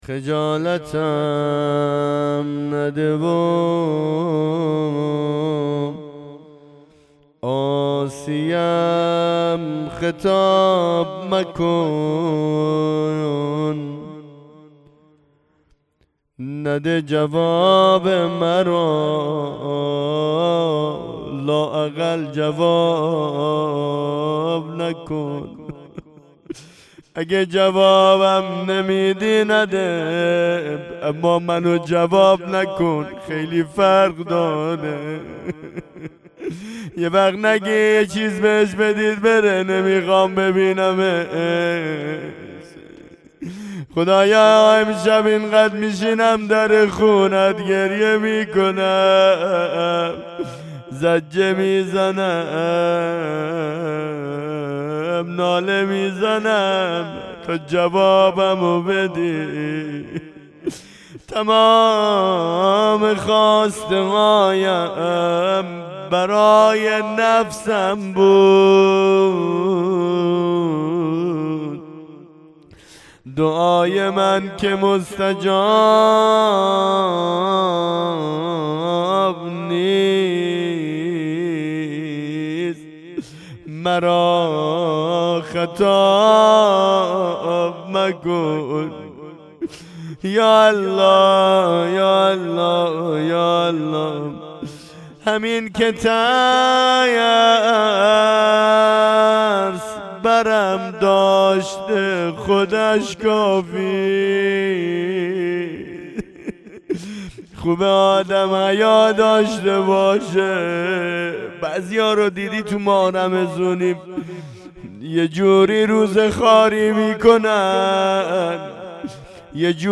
مناجات با خدا
هیئت رایة الزهرا سلام الله علیها یزد